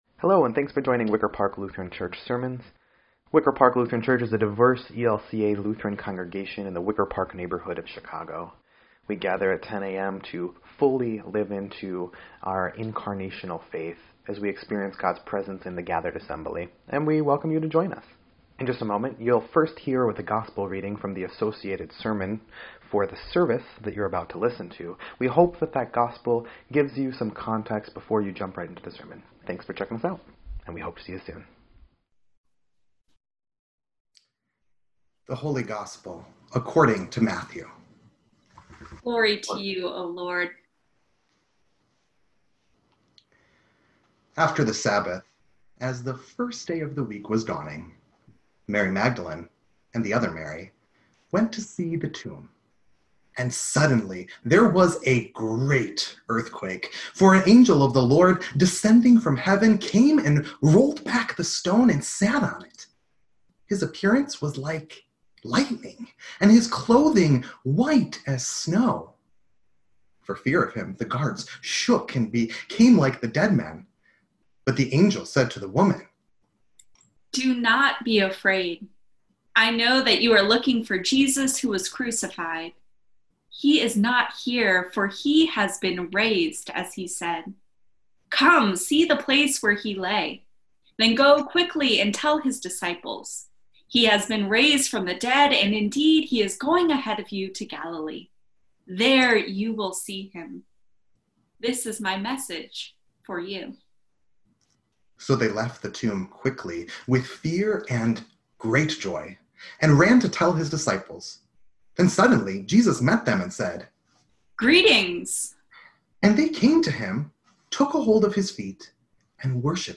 4.12.20-Sermon.mp3